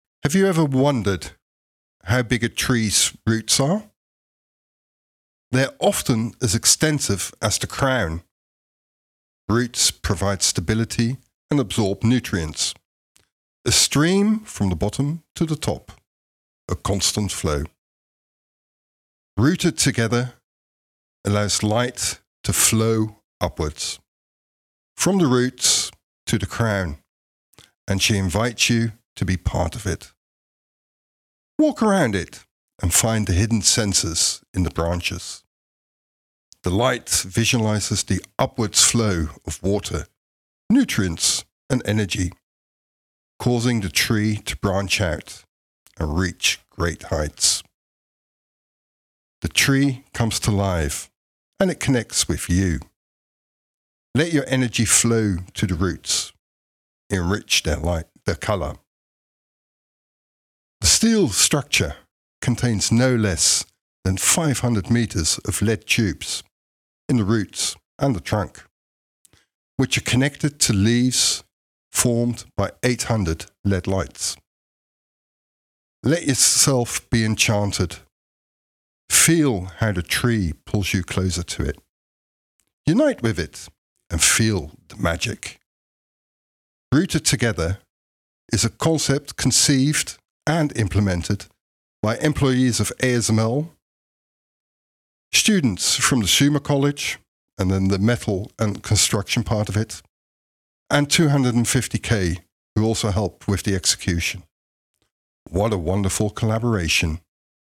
glow-audio-tour-rooted-together.mp3